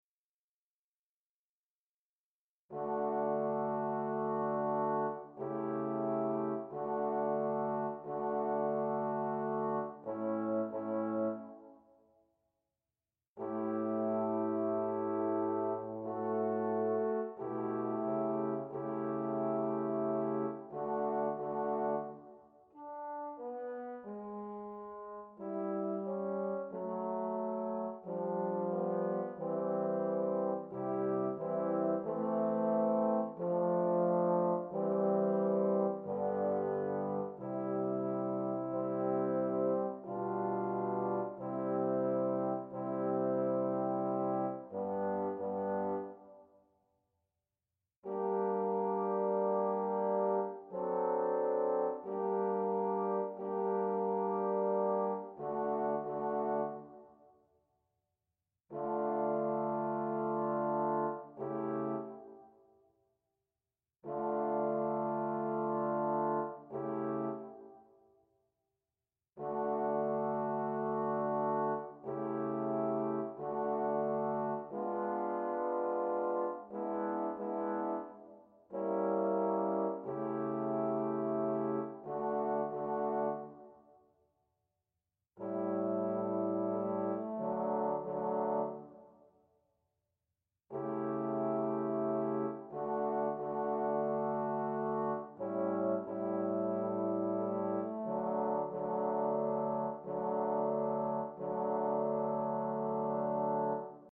pour 4 trombones